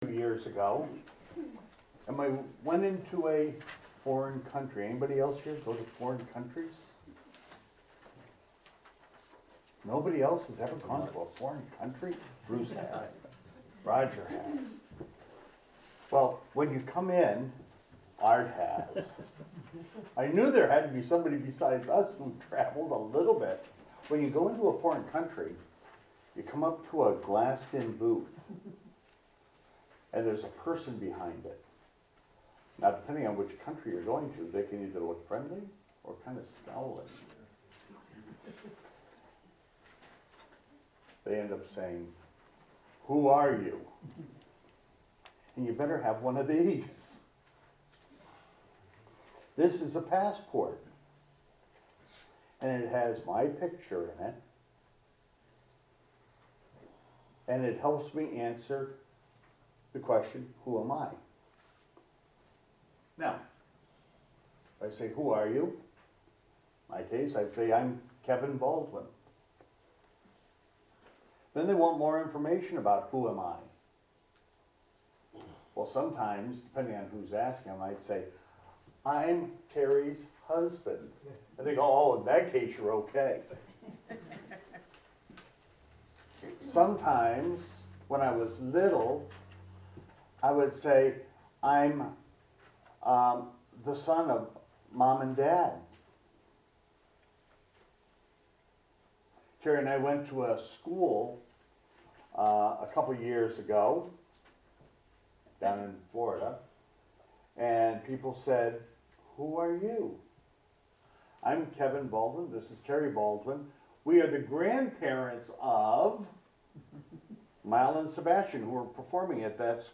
Archive Service Type: Sunday Worship Preacher